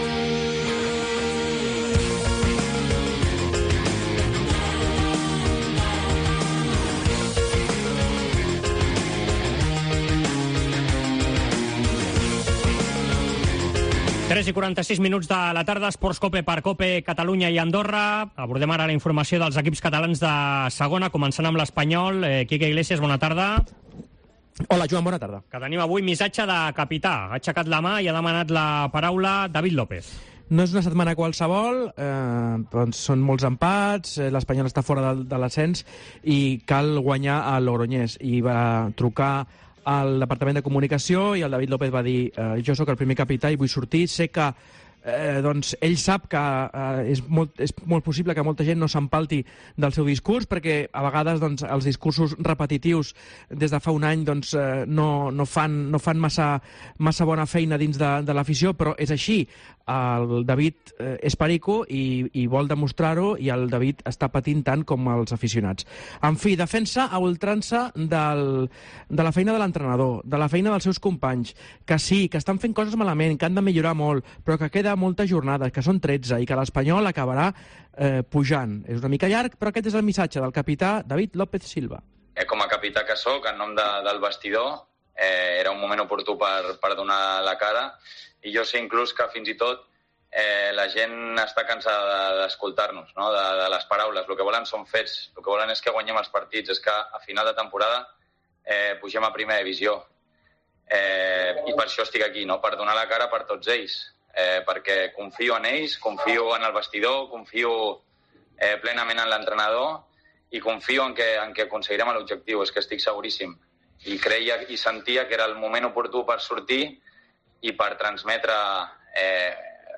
El capità de l'Espanyol David López ha comparegut en roda de premsa per petició própia aquest dimecres per llençar un missatge de tranquil·litat i d'unitat davant la situació delicada que travessa l'equip de Vicente Moreno.